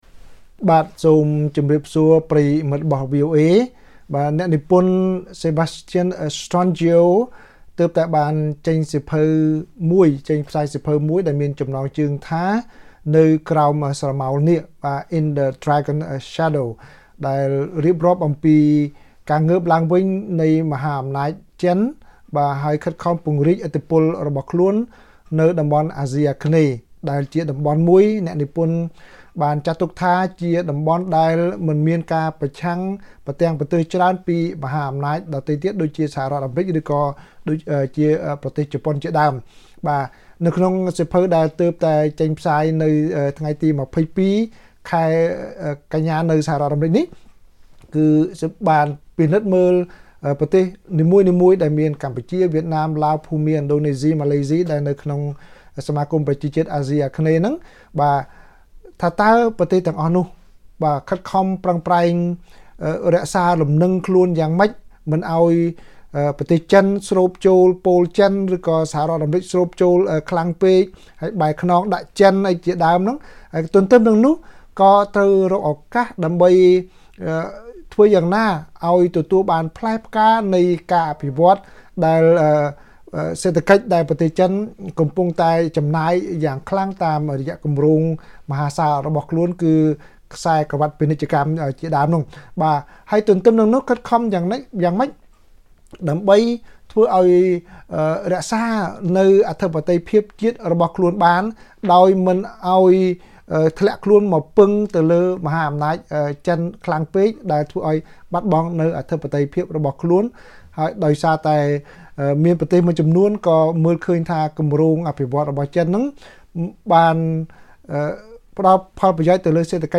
បទសម្ភាសន៍ VOA៖ សៀវភៅ«នៅក្រោមស្រមោលនាគ»បង្ហាញពីការពង្រីកអំណាចចិននៅអាស៊ាន